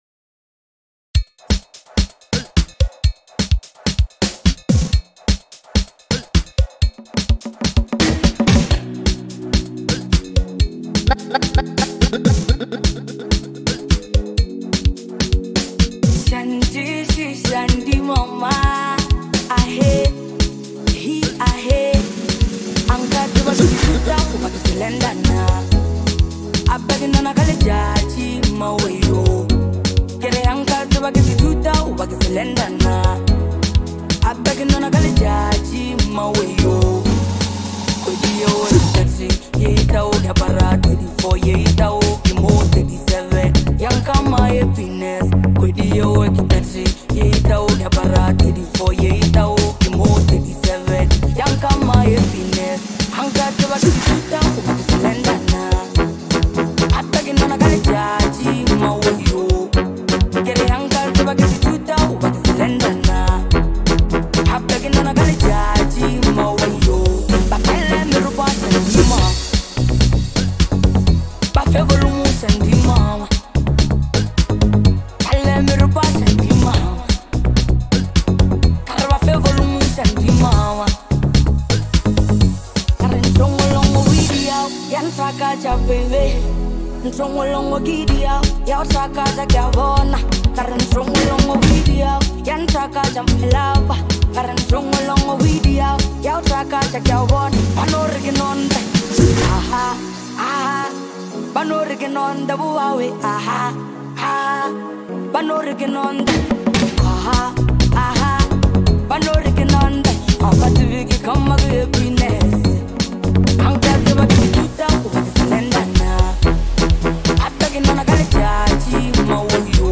a feel good anthem